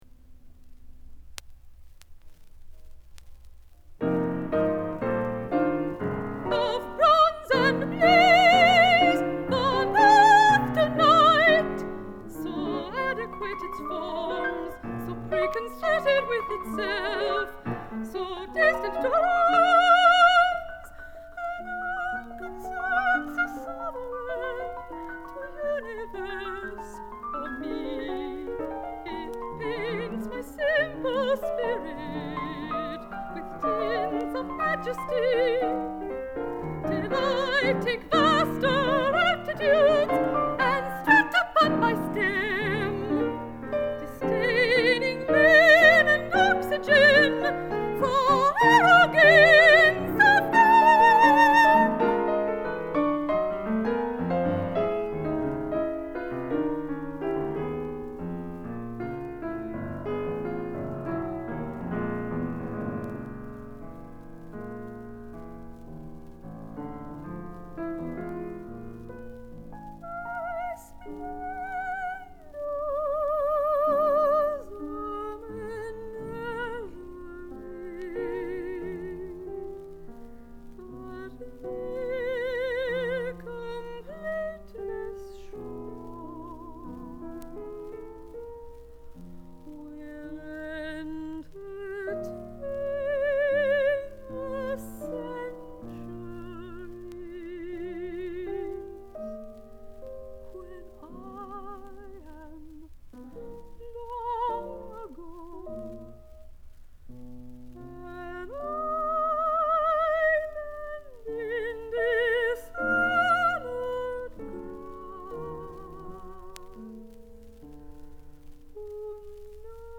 mezzo
piano